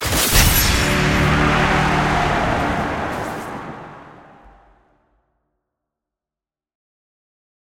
sfx-champstinger-bravery.ogg